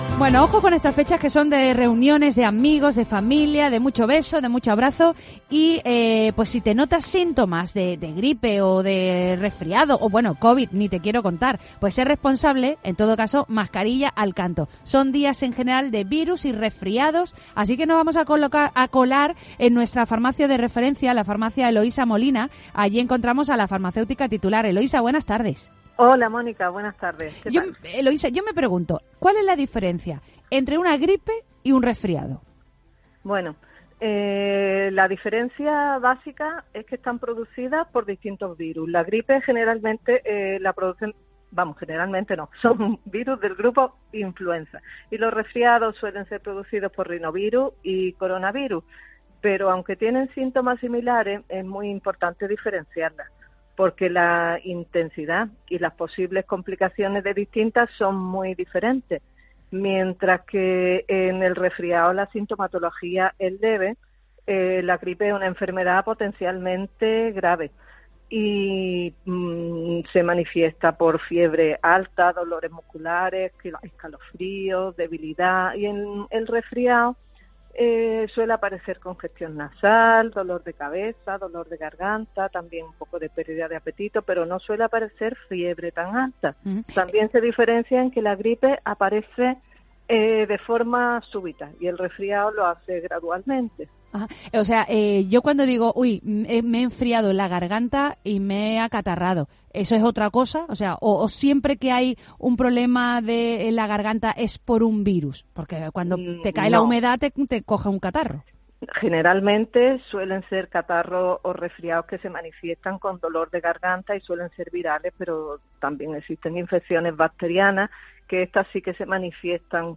¿Gripe o resfriado? Una experta explica las diferencias de estos procesos víricos